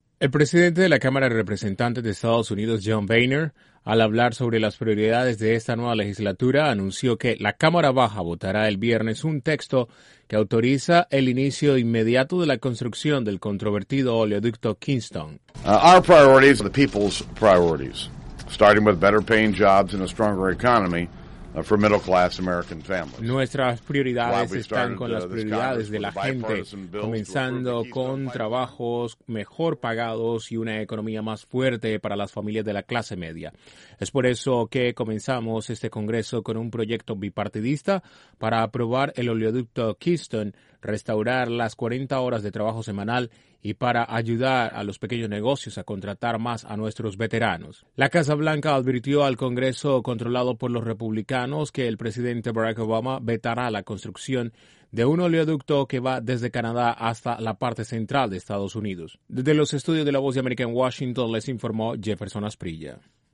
Los Republicanos en el Congreso de EEUU insisten en la construcción de un controvertido oleoducto y desestiman amenaza de veto de Obama. Desde la Voz de América en Washington informa